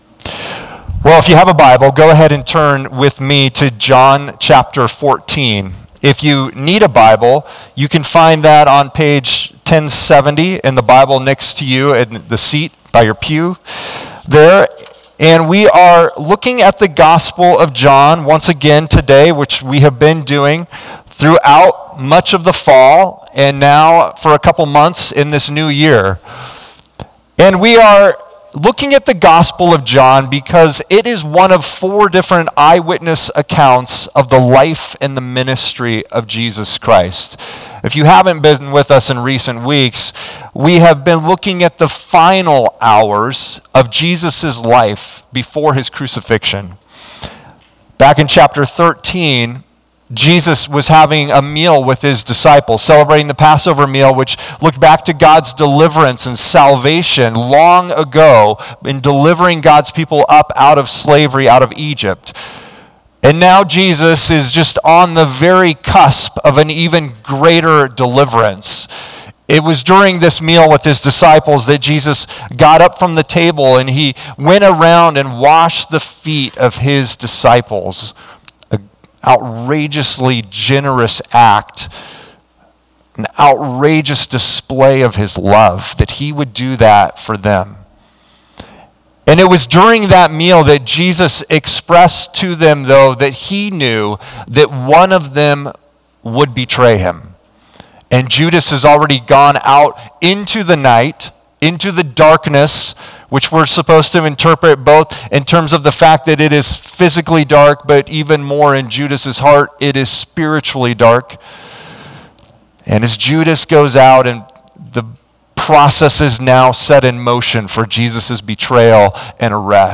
a thoughtful conversation through John 11, the story of Lazarus.